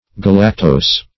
Galactose \Ga*lac"tose\, n. (Chem.)